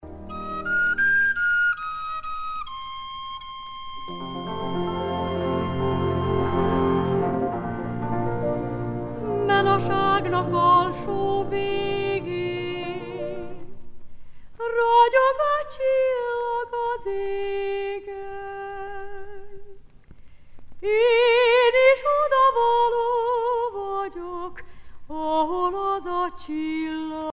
Székely folk songs